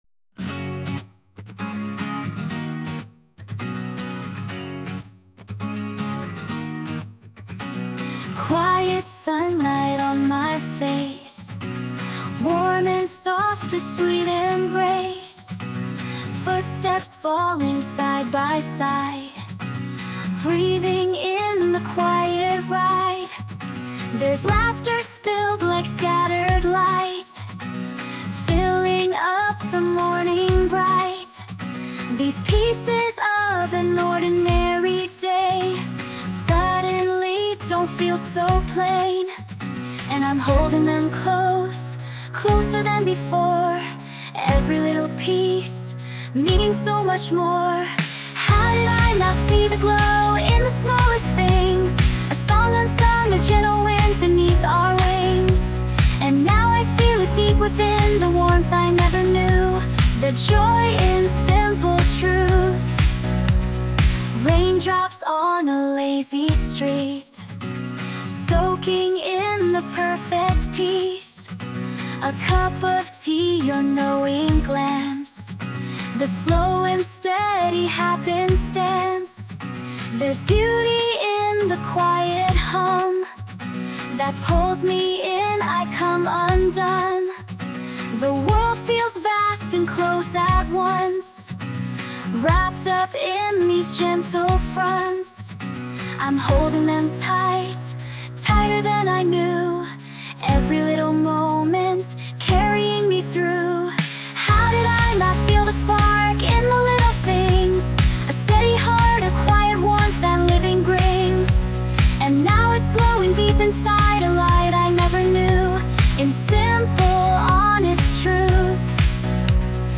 著作権フリーオリジナルBGMです。
女性ボーカル（洋楽・英語）曲です。
ウェディングソングというわけではないのですが、アップテンポで明るい曲なので、結婚式のオープニングにもピッタリ♪♪